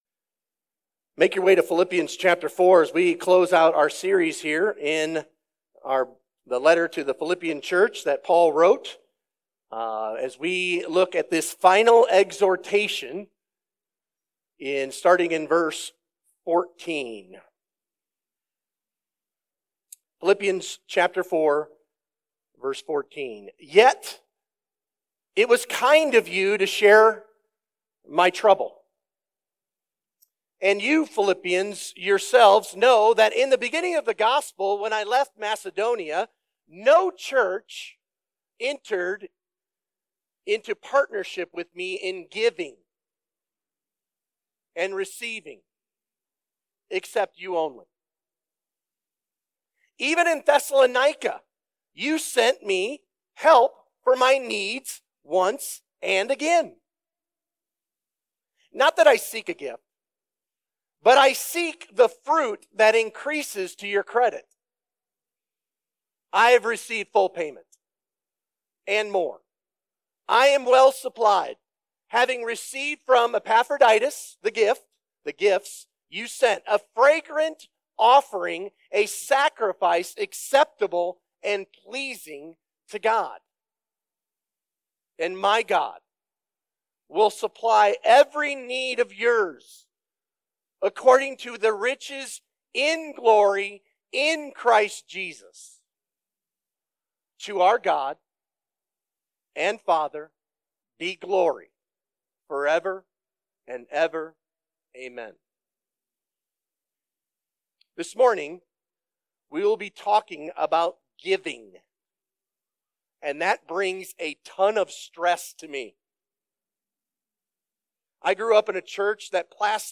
Sermon Questions How has the whole letter to the Philippian believers shown us what "Gospel partnership" is?